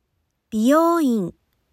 Click the audio bar to hear how they sound differently!